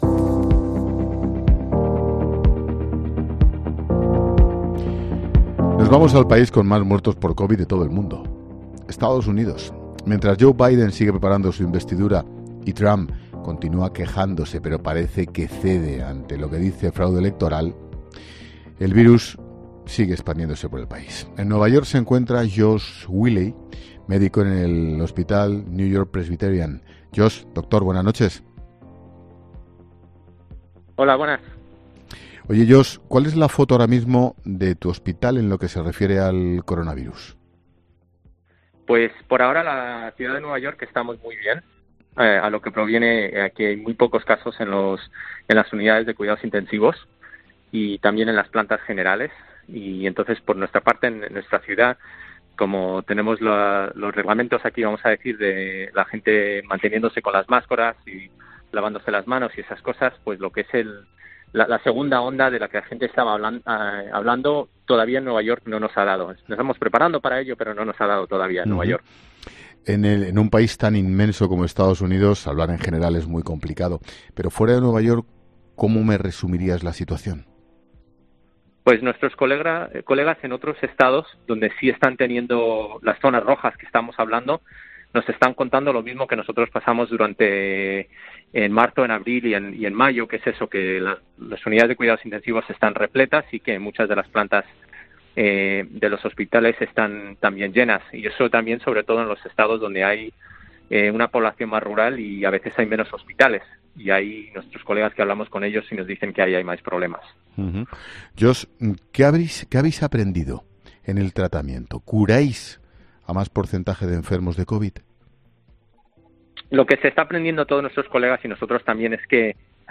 Lo hemos hecho con médicos españoles que luchan cada día para evitar que el virus se expanda por el mundo.